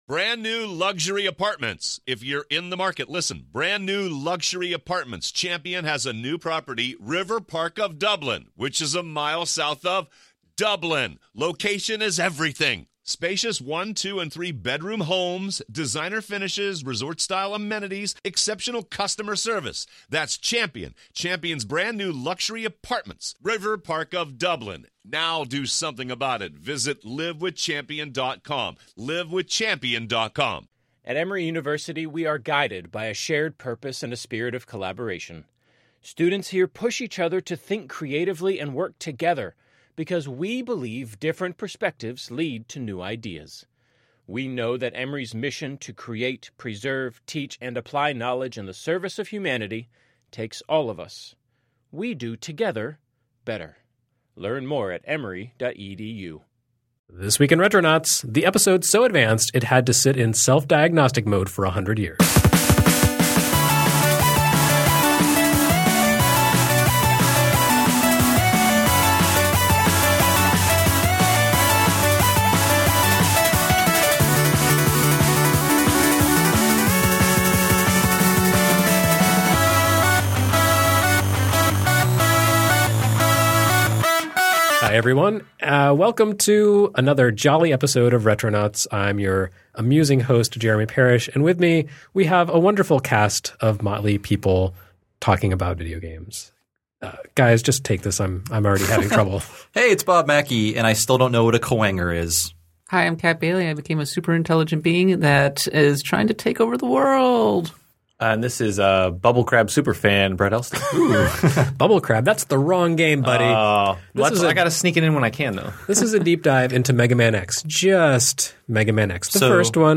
A nonlinear podcast adventure!